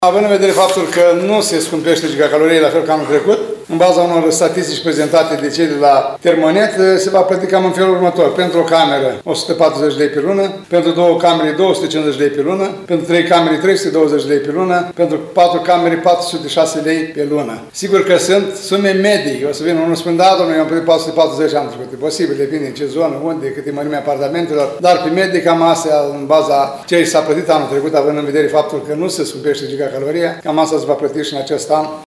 Primarul ION LUNGU a declarat astăzi că tariful achitat de consumatorii casnici din municipiul Suceava este cel mai mic la nivel național, adică 240 lei gigacaloria.